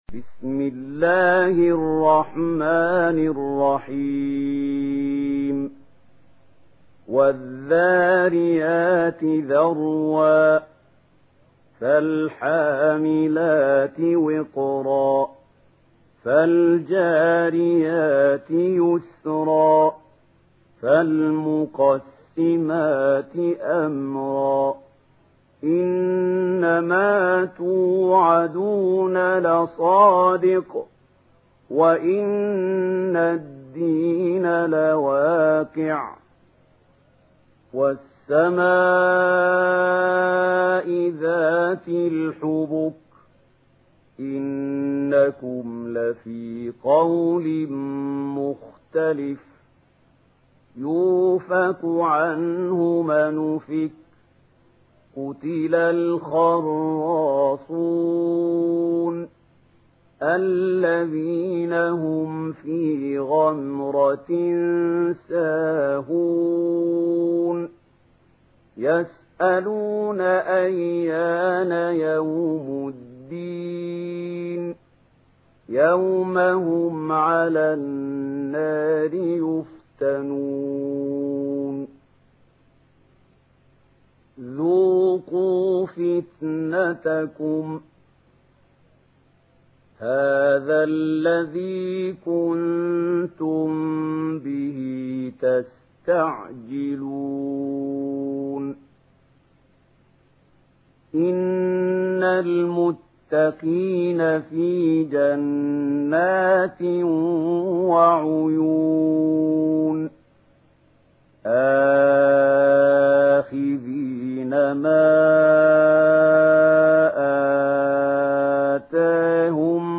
Une récitation touchante et belle des versets coraniques par la narration Warsh An Nafi.
Murattal